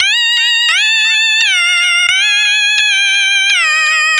03_Marz_173_Bb.wav